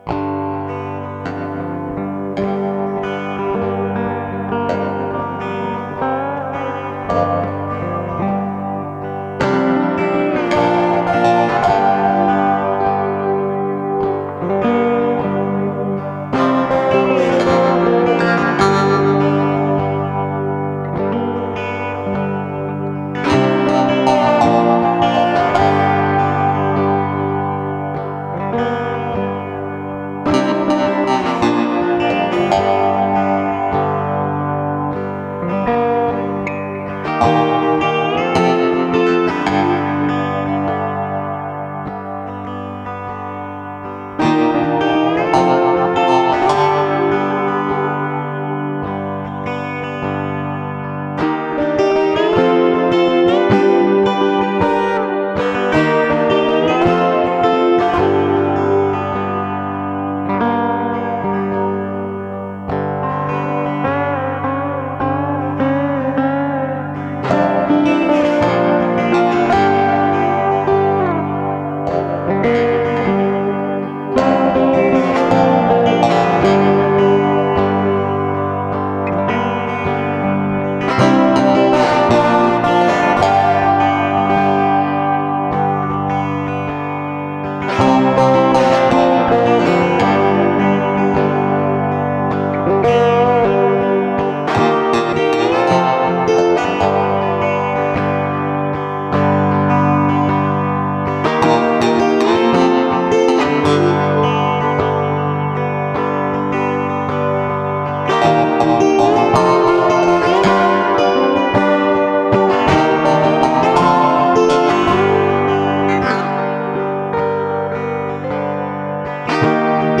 Psychedelic Western Folk. Chlling relaxed.